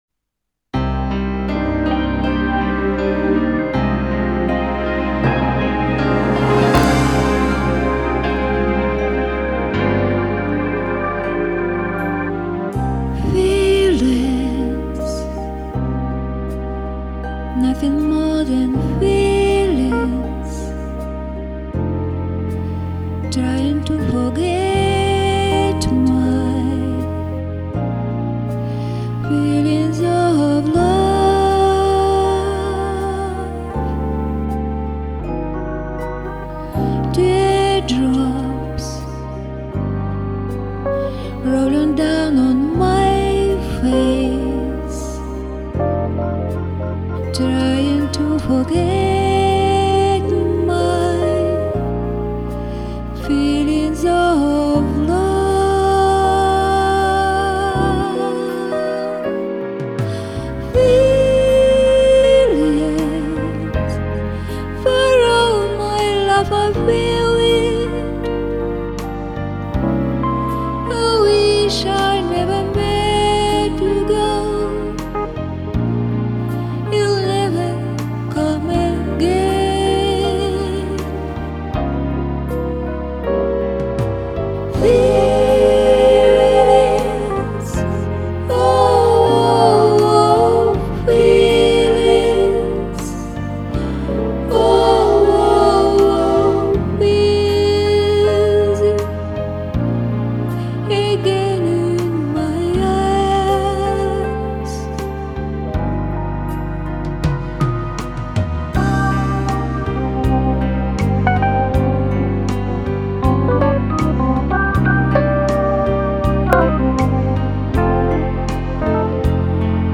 Ну ооочень романтичная песня о любви!